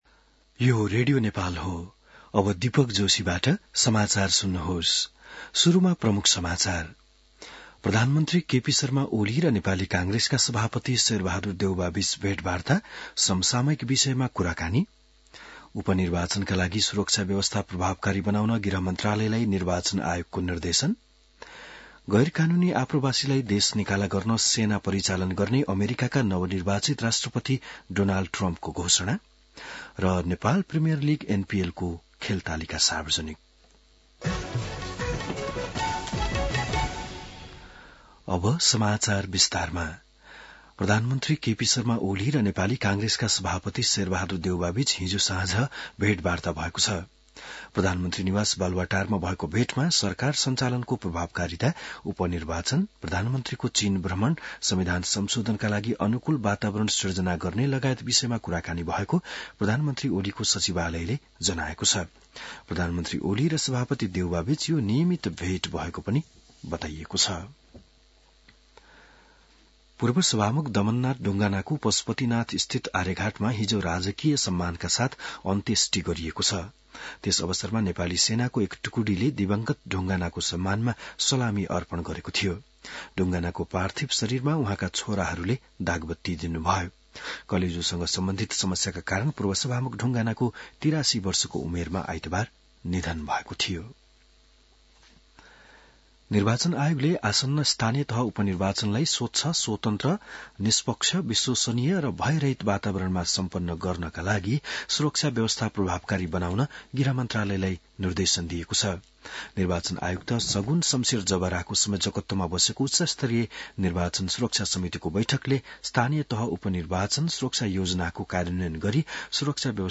बिहान ९ बजेको नेपाली समाचार : ५ मंसिर , २०८१